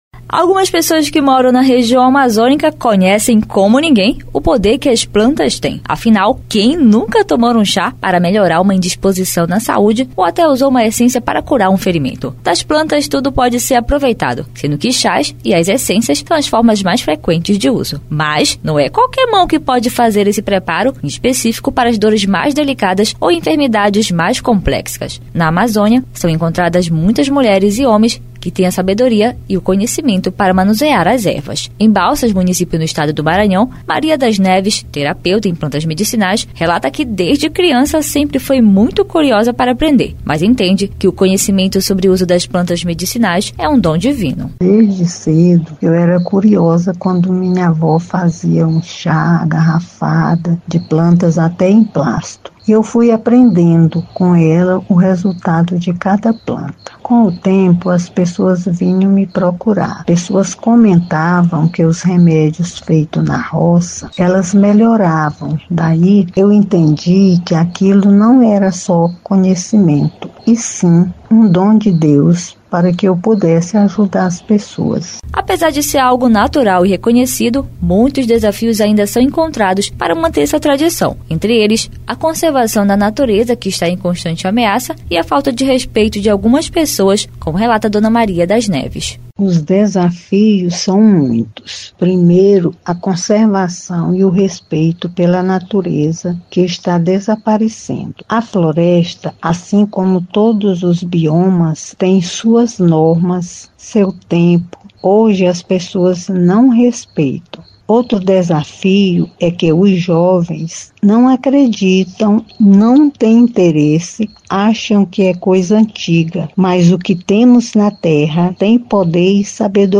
Mulheres relatam desafios sobre o uso das plantas medicinais na Amazônia